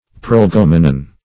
Prolegomenon \Prol`e*gom"e*non\, n.; pl.